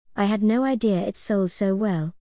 この音に関してはアメリカ発音で/oʊ/と発音する部分をイギリス発音では/əʊ/と発音します。
▶イギリス英語